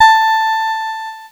Cheese Note 15-A3.wav